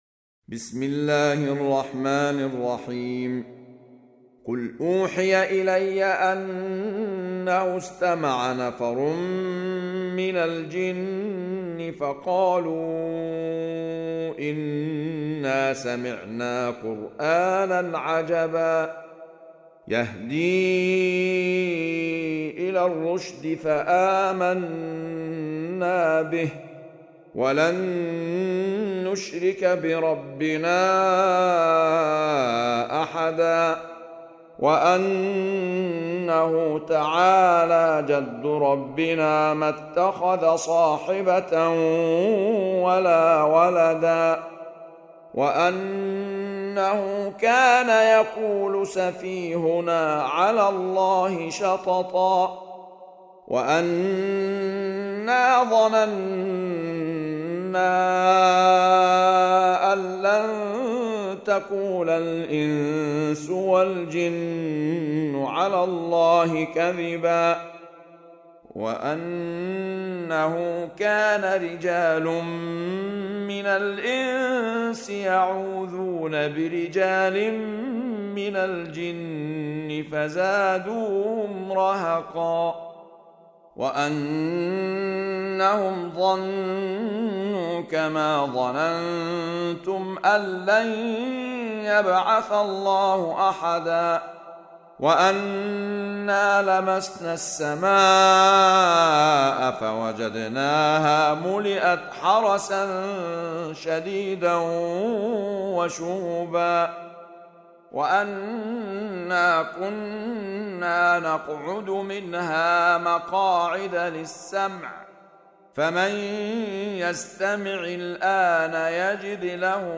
سورة الجن | القارئ أحمد عيسي المعصراوي